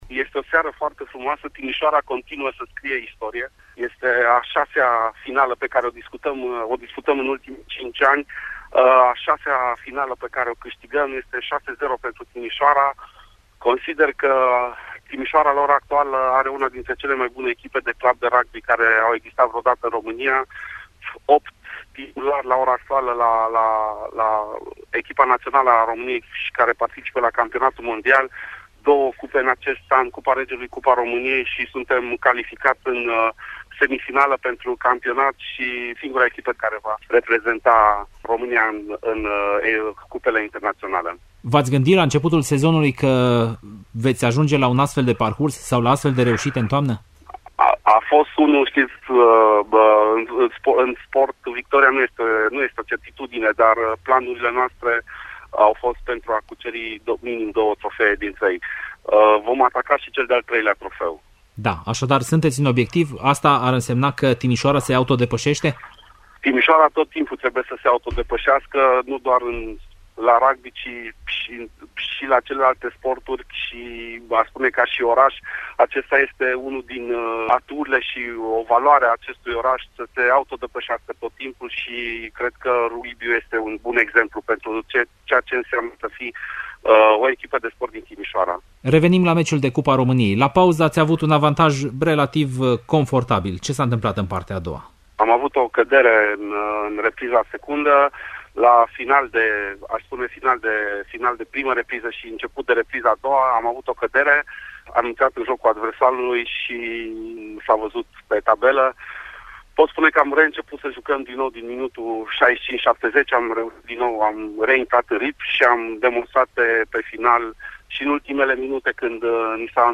la câteva minute după încheierea festivităţii de premiere